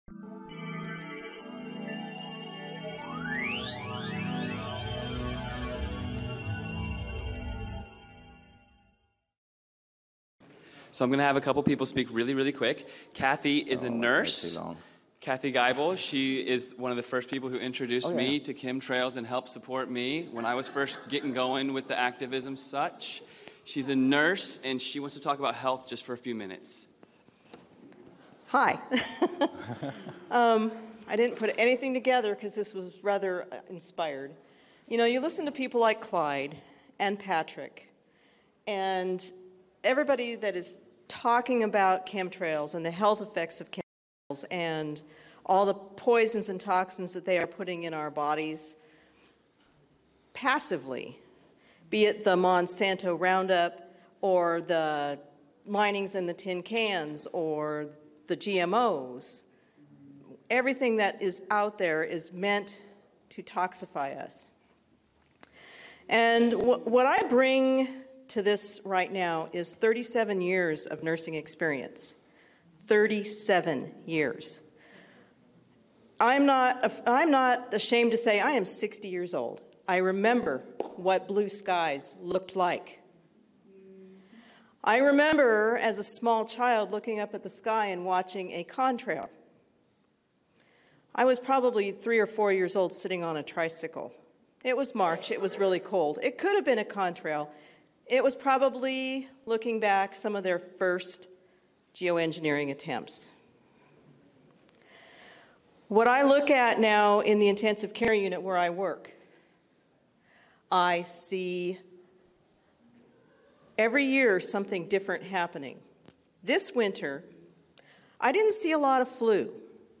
Nurse Testimony On Chemtrail Health Consequences